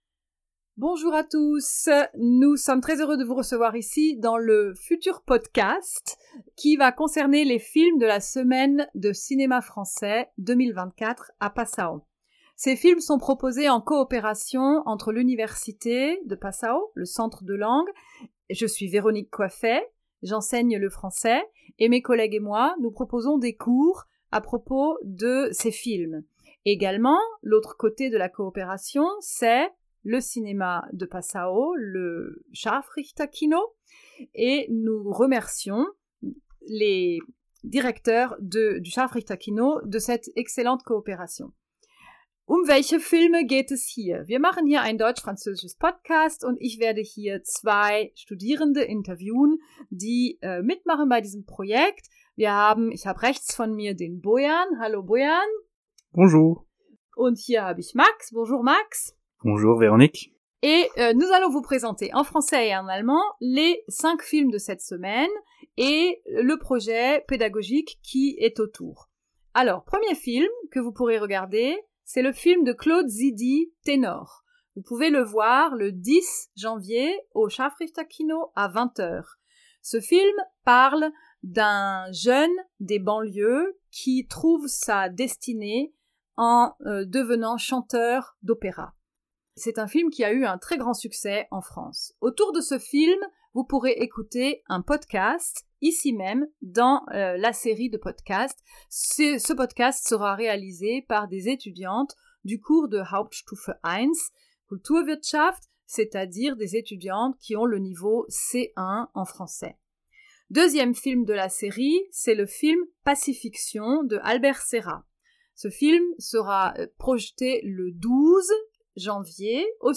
[00:00:44] Speaker B: Wir machen hier ein deutsch-französisches Podcast und ich werde hier zwei Studierende interviewen, die.